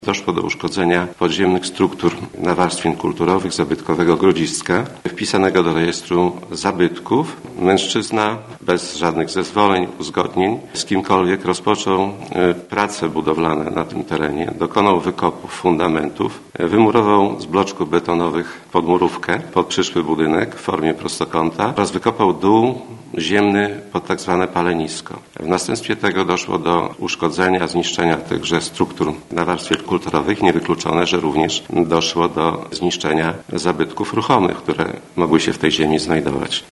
Sprawą już w połowie października zajęła się ełcka prokuratura. Mówi jej szef Jan Mikucki.